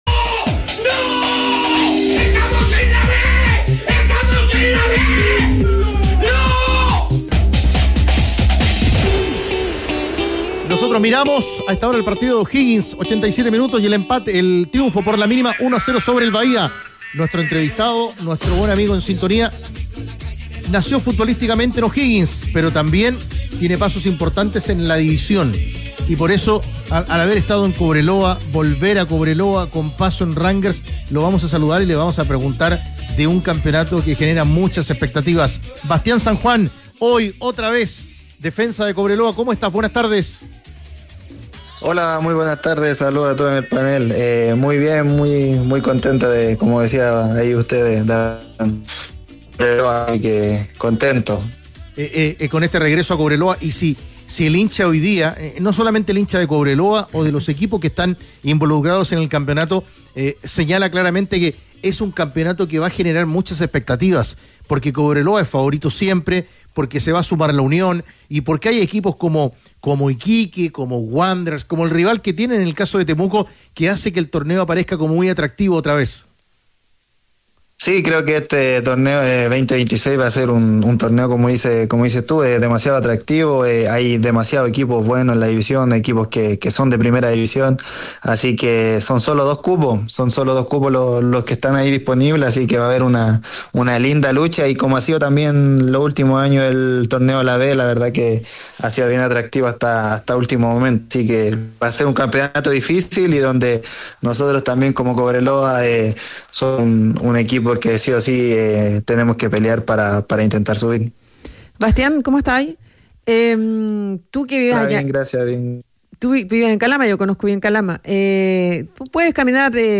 En conversación con Los Tenores de la Tarde